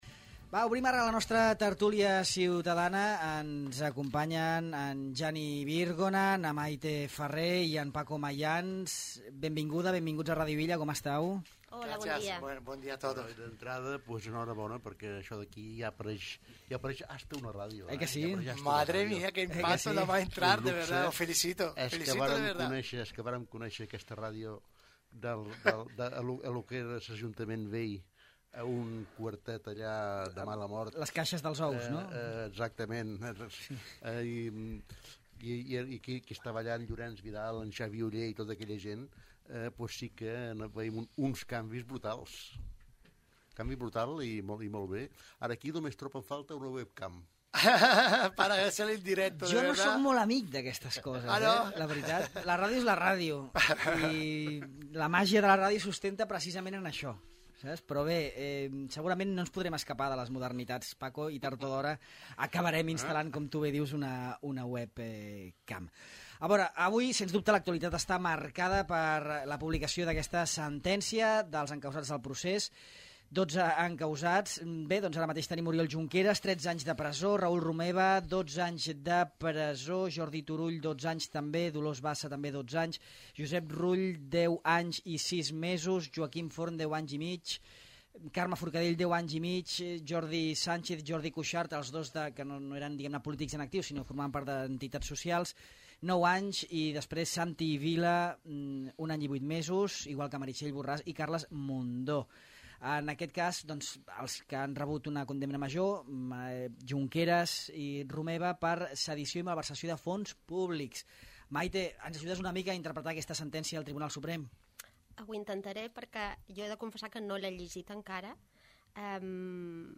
La Tertúlia Ciutadana aborda el tema del dia: la sentència del cas Procés